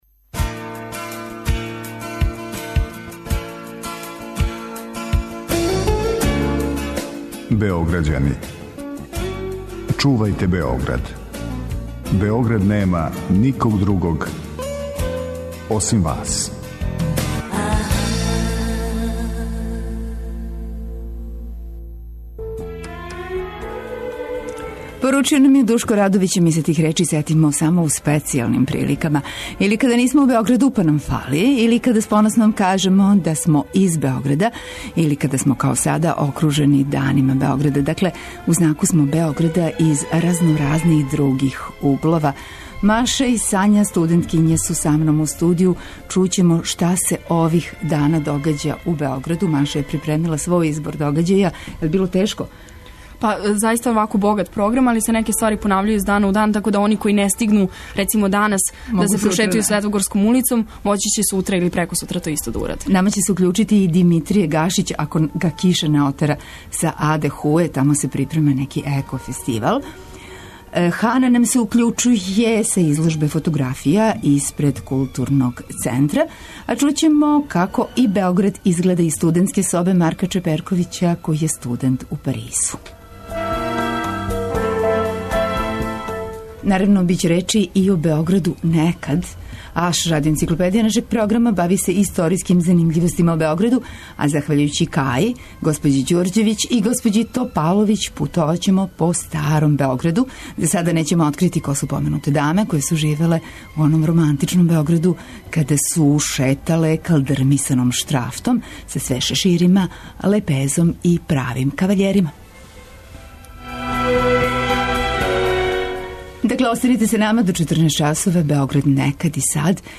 Главни лик емисије је Београд - из разноразних других углова. Гости су средњошколци и студенти који су направили сопствени избор интересантних догађања, које нам препоручују да посетимо ових дана у Београду.